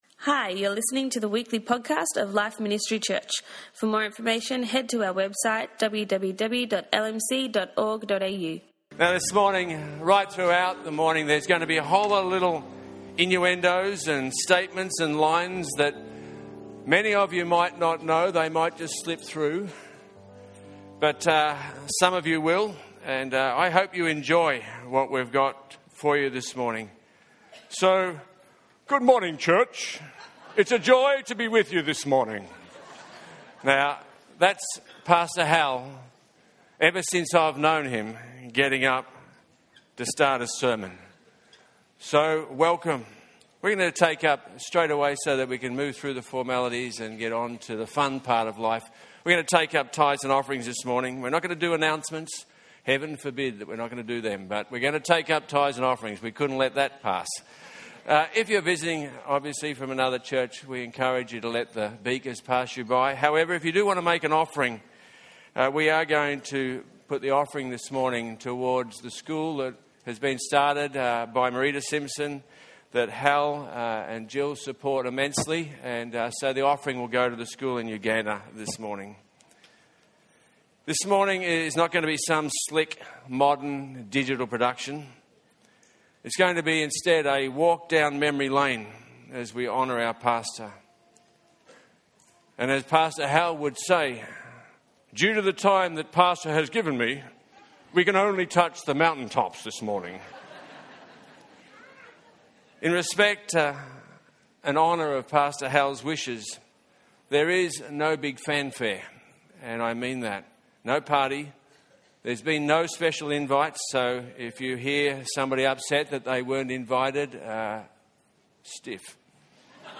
Celebration Service